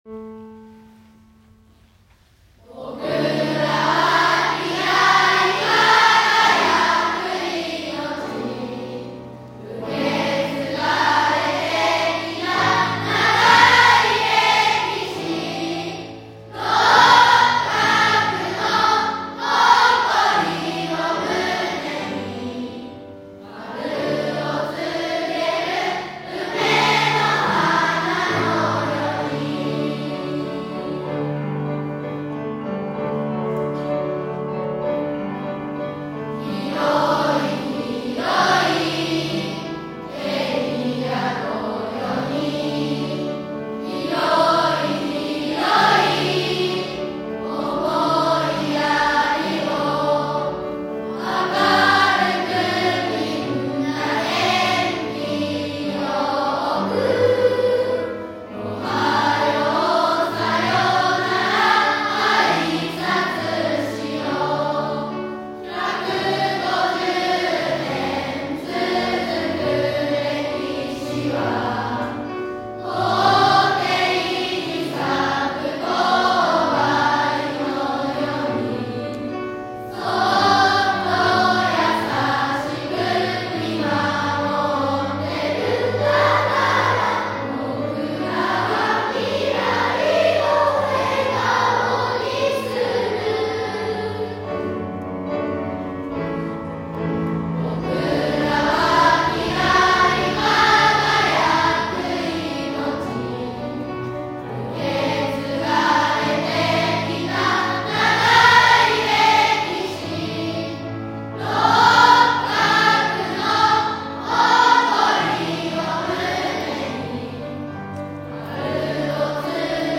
全校児童で歌う「六角の誇り～紅梅スマイル～」